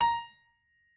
pianoadrib1_28.ogg